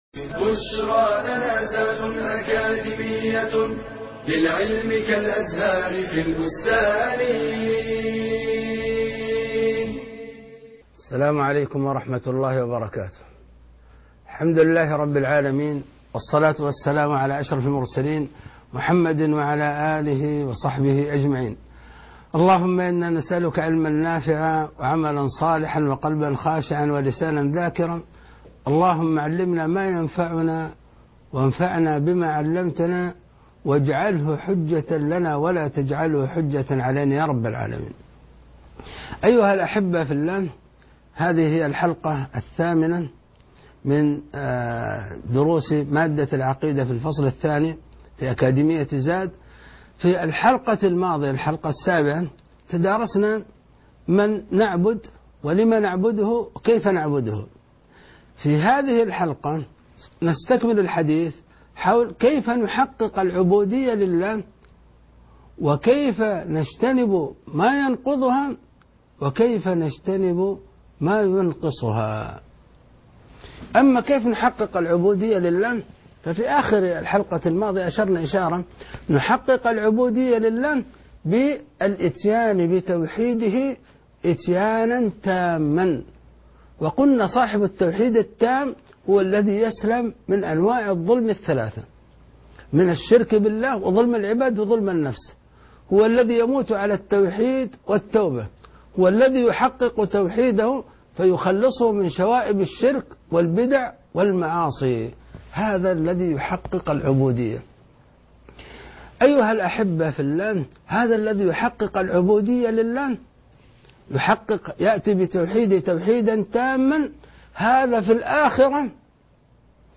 المحاضرة الثامنة _ التوحيد الخالص